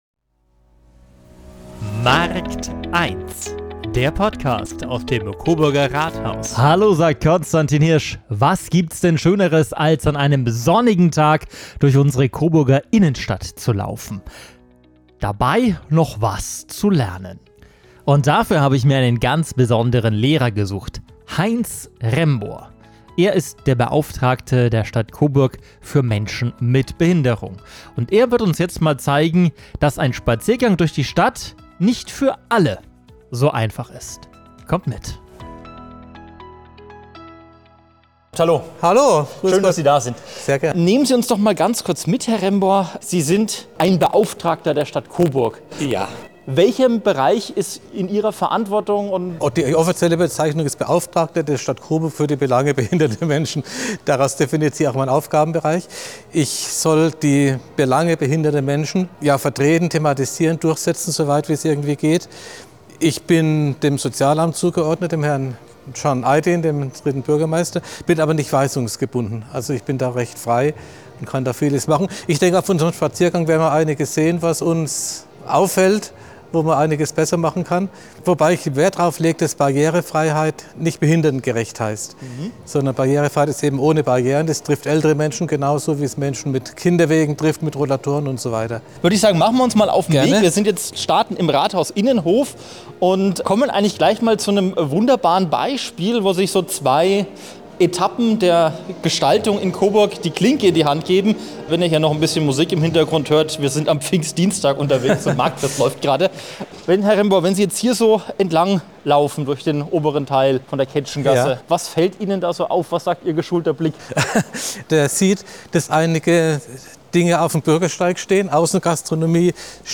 Stadtspaziergang mit dem Behindertenbeauftragten
In dieser Folge von Markt 1 nehmen wir Sie mit auf einen kritischen Spaziergang durch die Innenstadt – gemeinsam mit Heinz Rembor, dem Beauftragten für Menschen mit Behinderung. Kopfsteinpflaster, Außengastronomie und fehlende öffentliche Toiletten sind nur einige der Hürden. Rembor erklärt, warum Barrierefreiheit alle betrifft, welche Fortschritte Coburg gemacht hat – und wo es noch hakt.